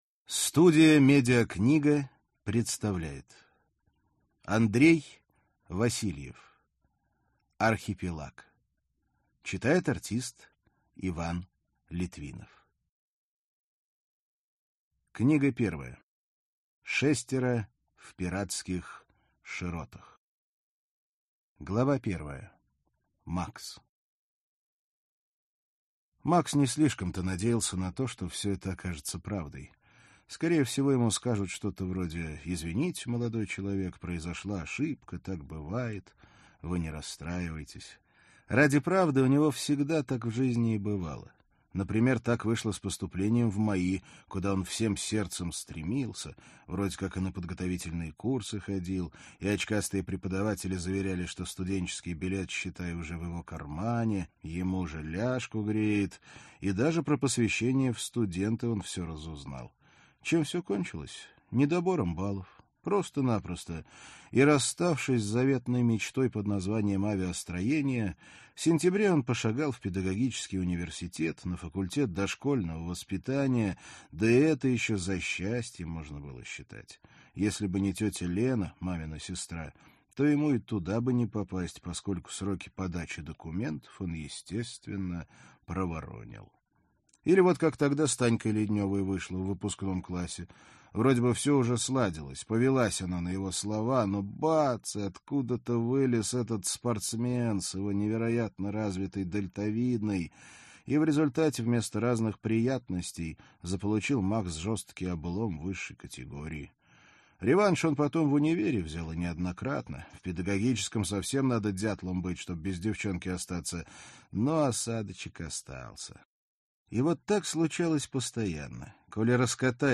Аудиокнига Архипелаг. Шестеро в пиратских широтах | Библиотека аудиокниг